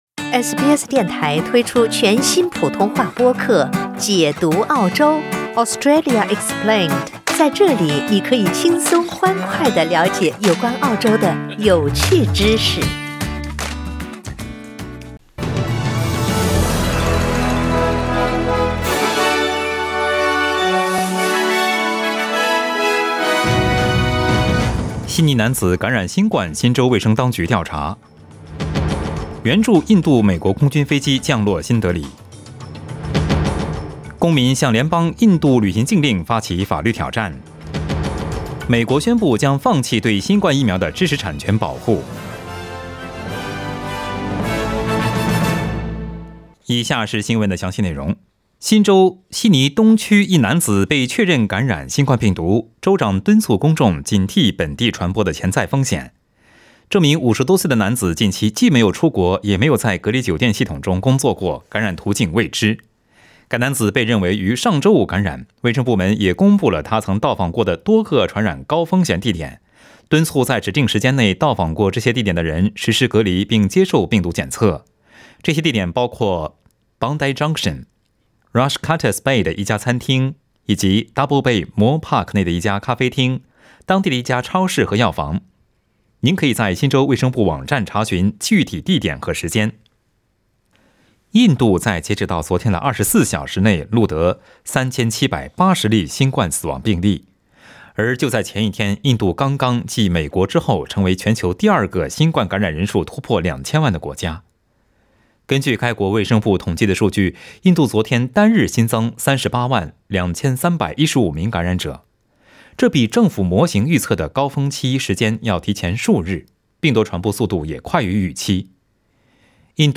SBS早新聞（5月6日）
SBS Mandarin morning news Source: Getty Images